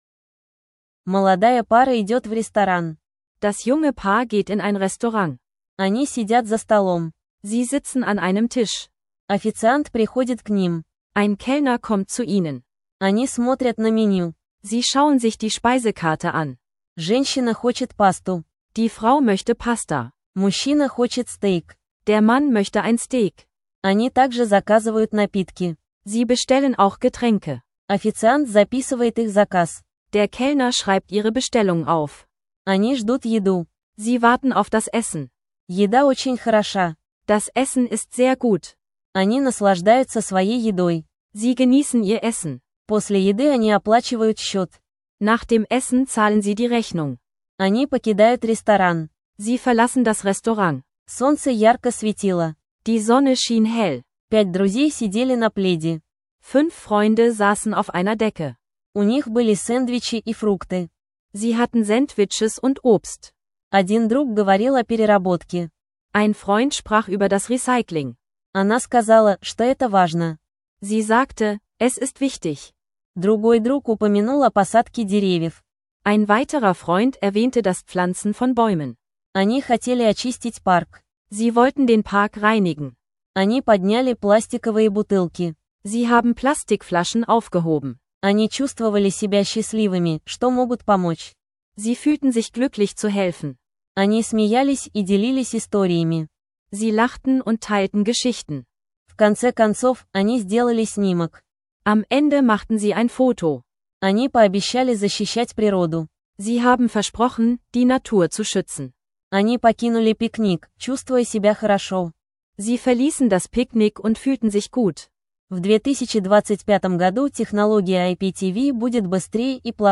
In dieser Episode erlebst du ein junges Paar, das im Restaurant